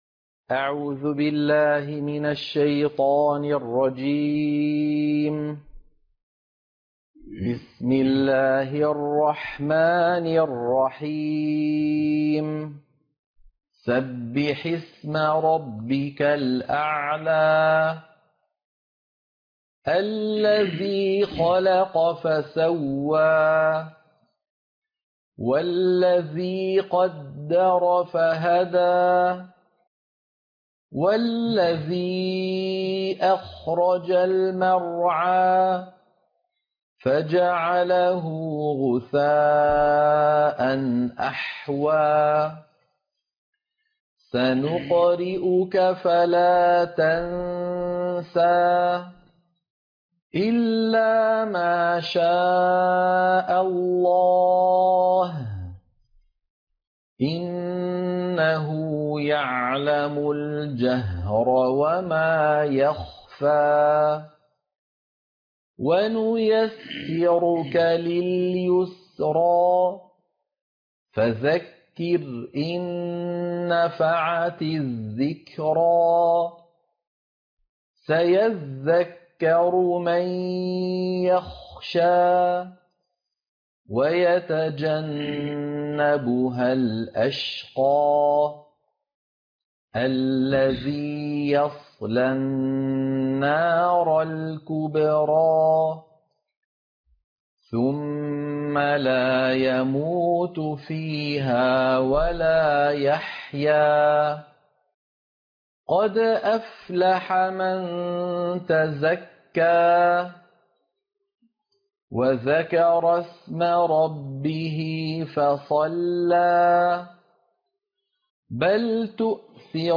عنوان المادة سورة الأعلى- القراءة المنهجية